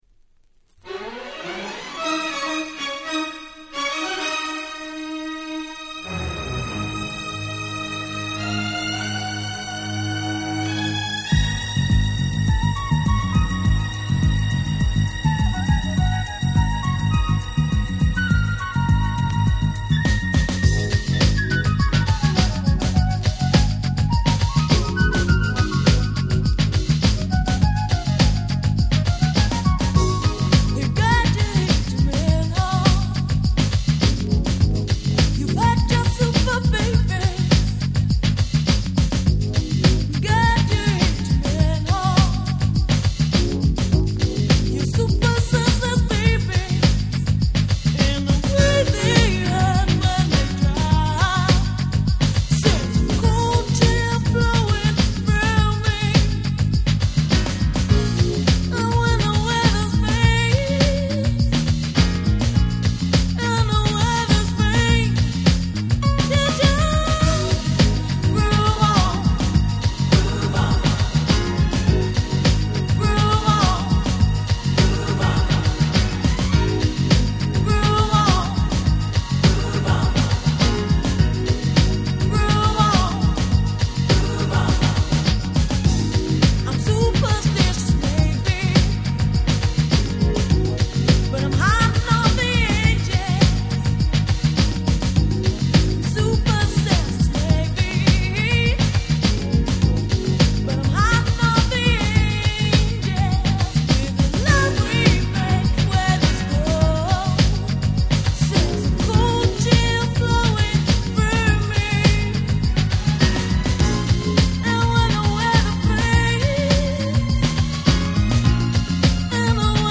91' UK Soul Classic !!
流れる様なMelodyも心地良い「まさにUK Soul !!」な一枚です。